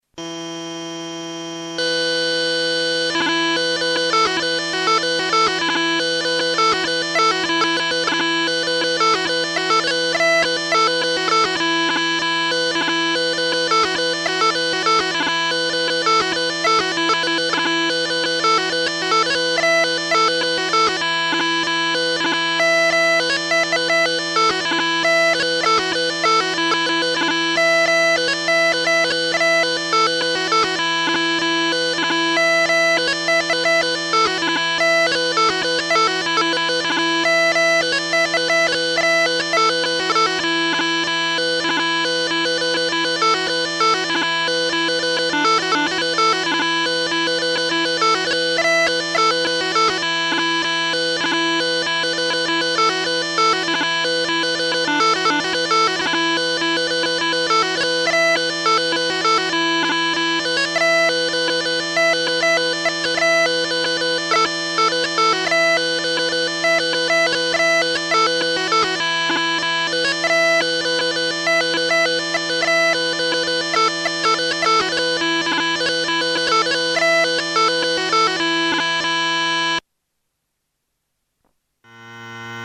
The redpipe is a brand of electronic bagpipes, an electronic musical instrument made to emulate the sound and characteristics of the bagpipe.
Electronic Scottish Smallpipe
Electronic_scottish_smallpipe.ogg.mp3